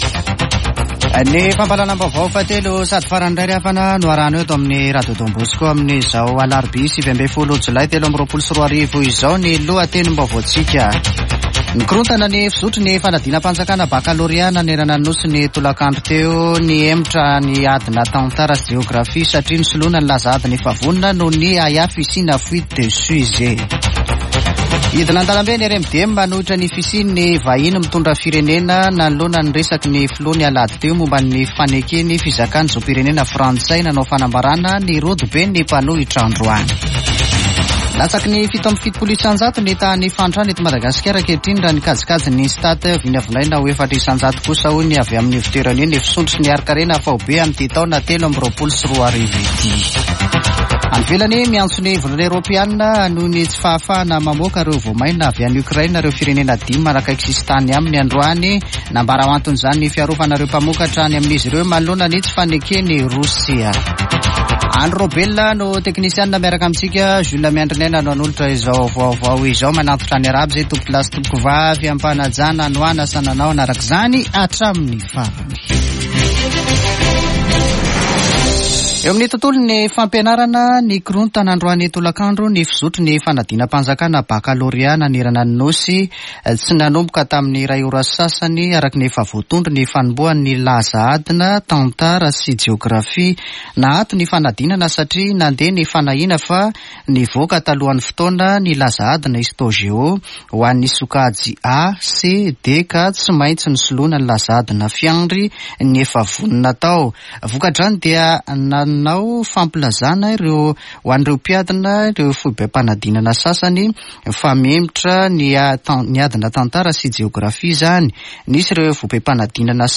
[Vaovao hariva] Alarobia 19 jolay 2023